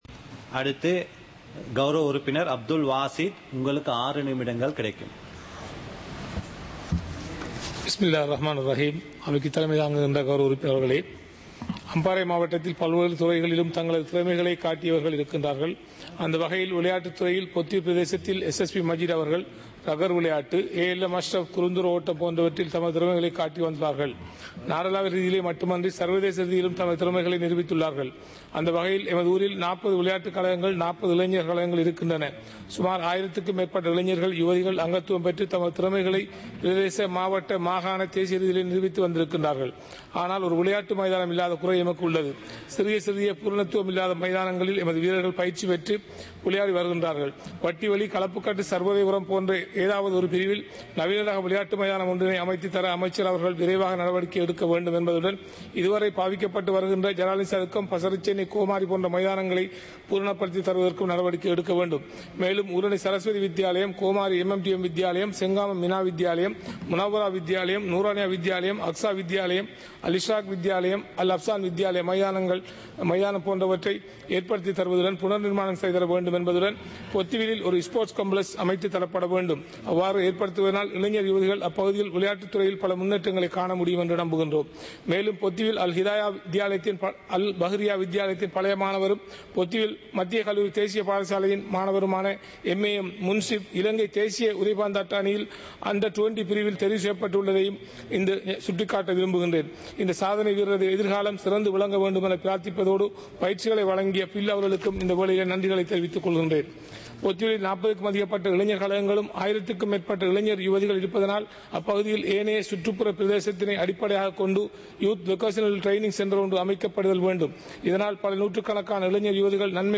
සභාවේ වැඩ කටයුතු (2026-02-19)
පාර්ලිමේන්තුව සජීවීව - පටිගත කළ